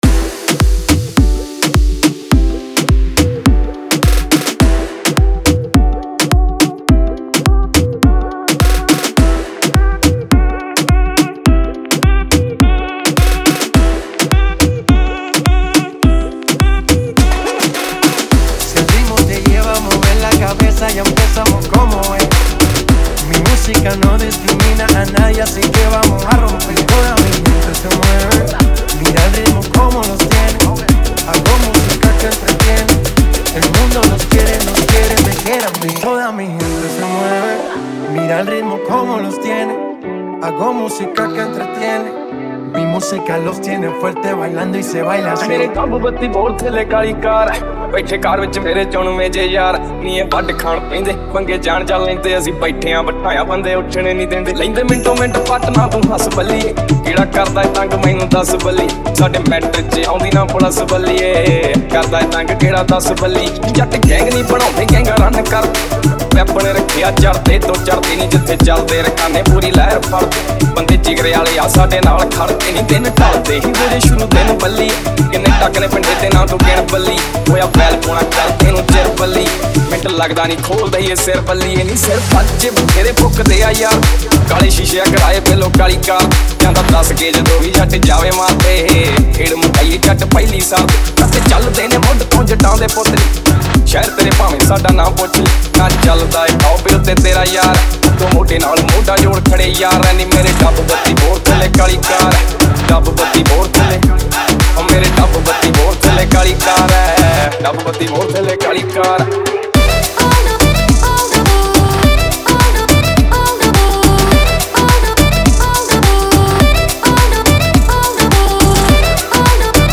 Punjabi DJ Remix Songs